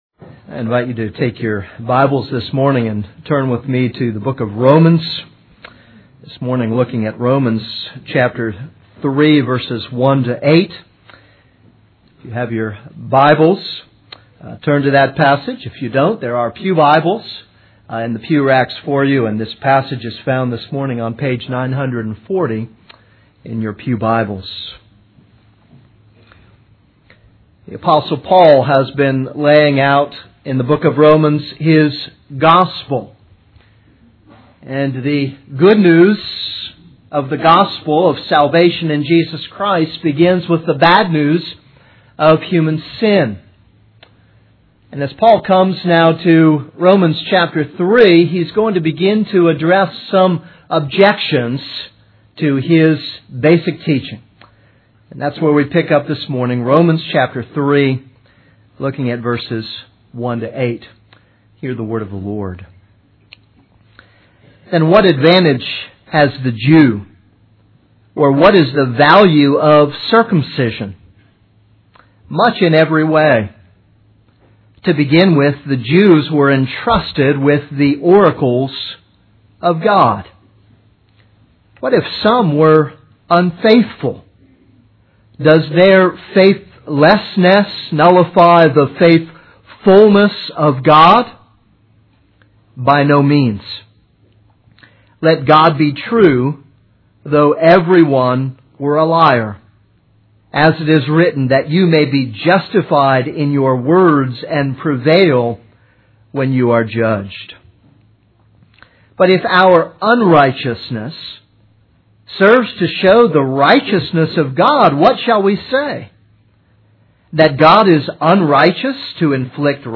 This is a sermon on Romans 3:1-8.